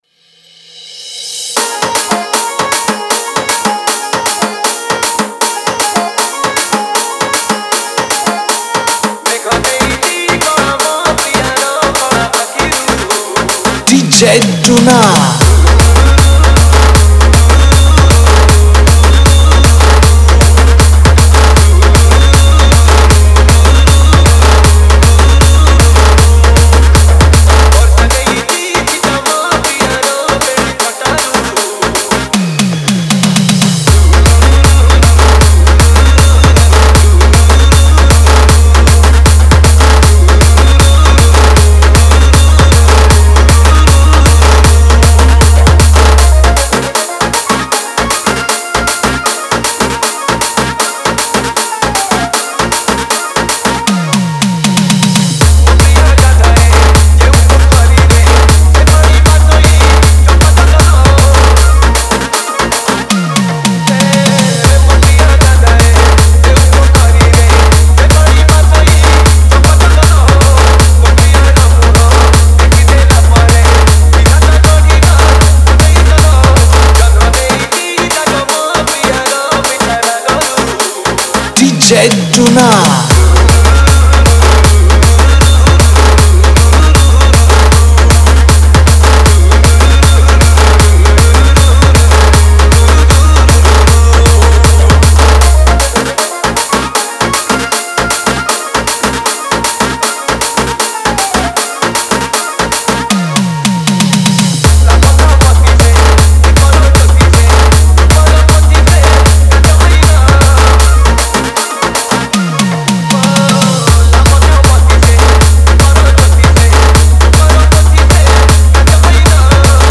Category : Odia Remix Song